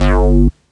cch_bass_one_shot_fingers_G.wav